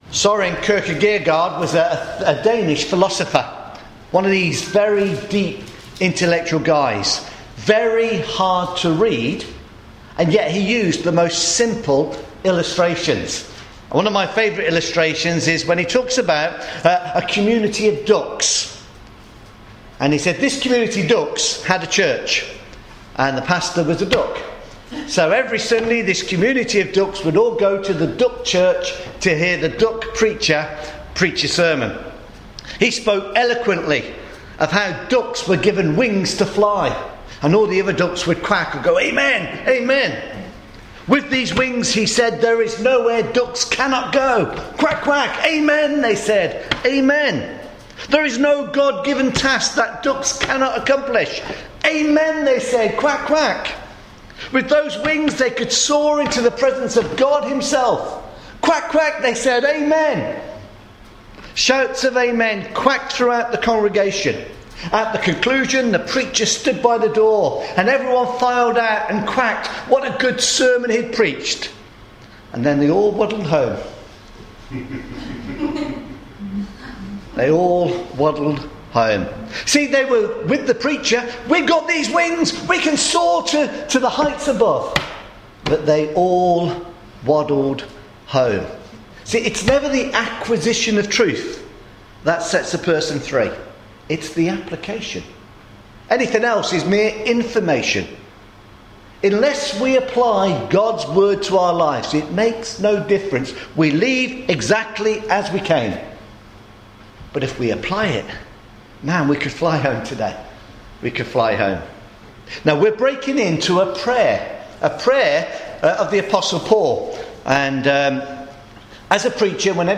Strengthening Your Grip (part 1) – Ephesians chapter 3 verses 14 to 25 – sermon